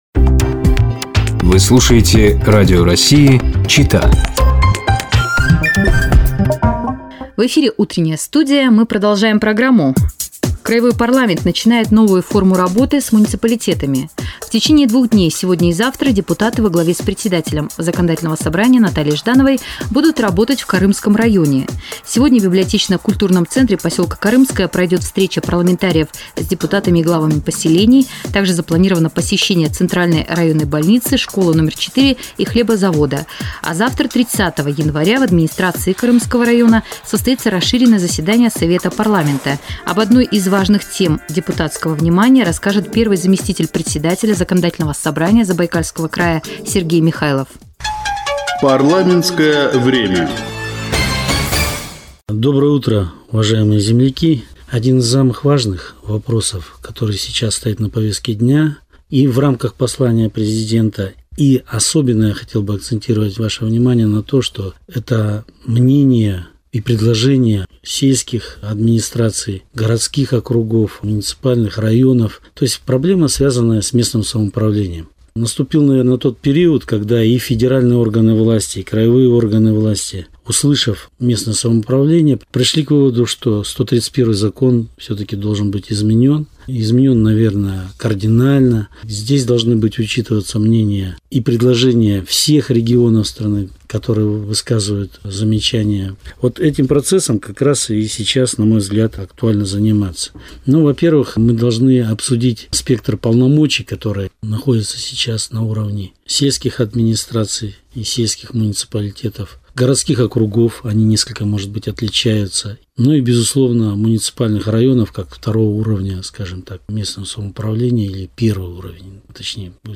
Каждую среду в эфире "Радио России-Чита" выходит рубрика "Парламентское время" . Слушайте выпуск за 29 февраля, в нем первый вице-спикер Заксобрания Сергей Михайлов рассказывает о новой форме работы парламента с муниципалитетами.